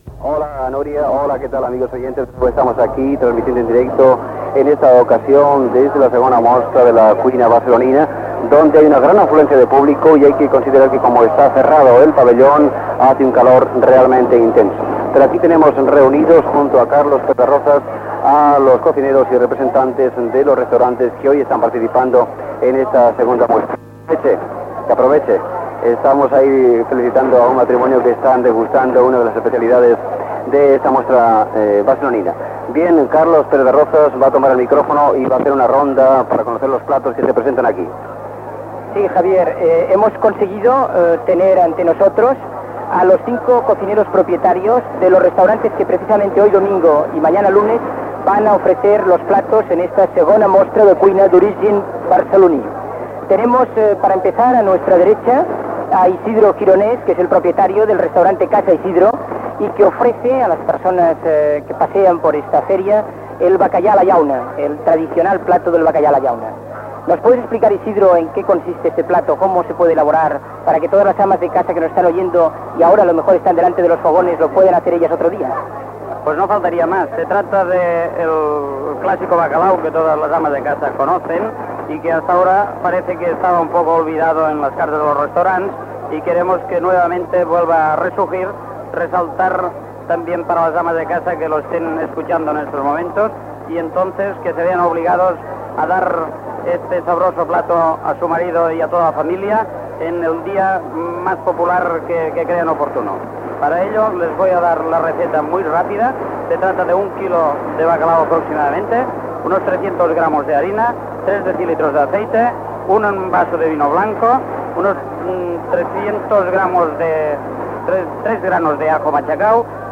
Connexió amb la II Mostra de la cuina barcelonina que es feia a la Rambla de Catalunya de Barcelona. Tres cuiners dels restaurants participants donen tres receptes.